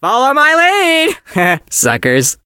chester_start_vo_11.ogg